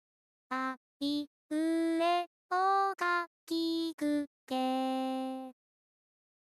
初音ミクの歌声は、AIによる自動調整によって、簡単にリアルな歌声になります
• AIによる自動調整で、ピッチや表情は明らかに自然になった